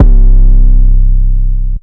808 12 [ hard knock ].wav